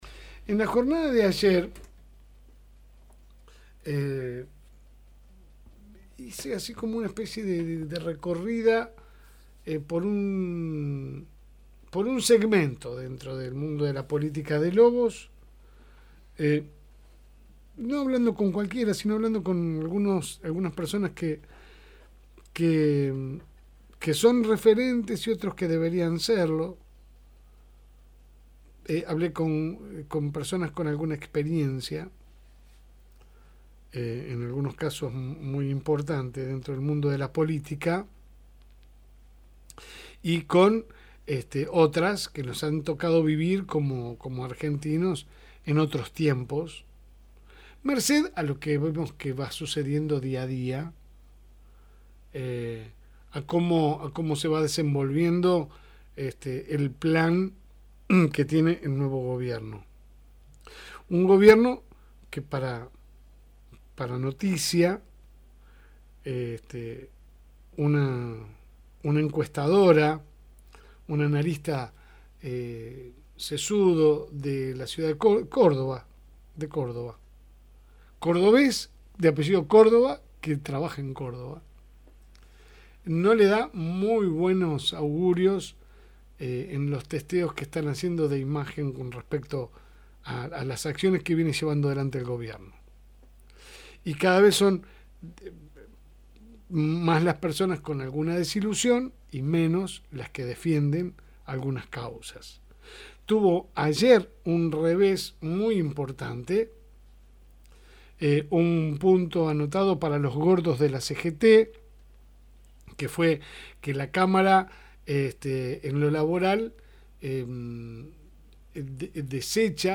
EDITORIAL – EL PRIMER REVÉS – FM Reencuentro